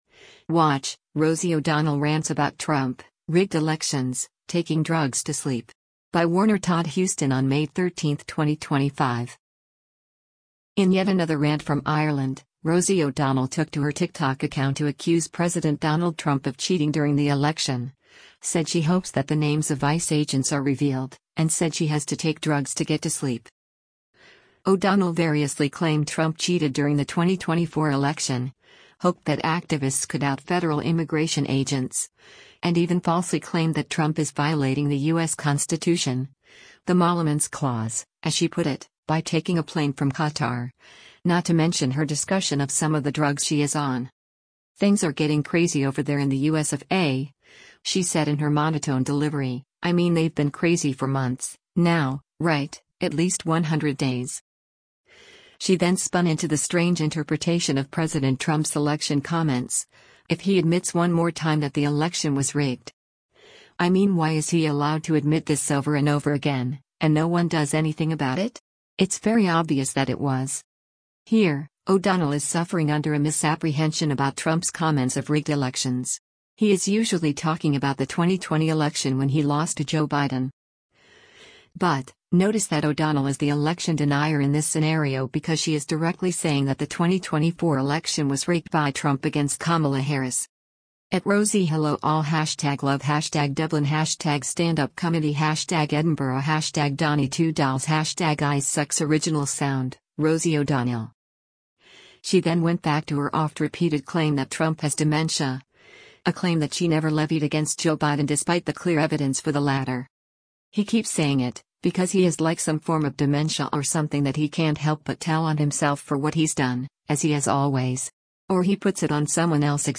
“Things are getting crazy over there in the U S of A,” she said in her monotone delivery, “I mean they’ve been crazy for months, now, right, at least 100 days.”
“Molluments clauses, everyone,” she gravely intoned.